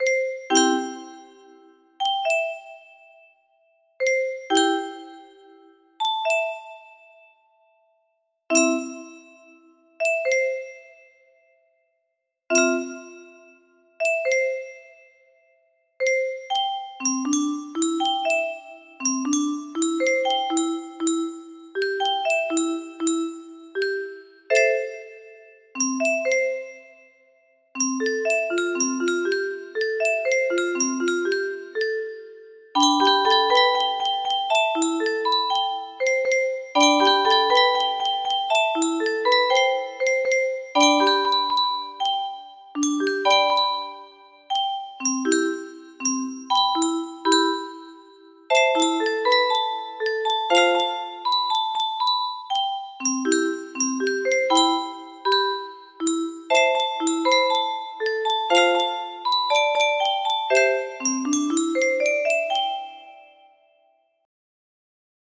Grand Illusions 30 music boxes More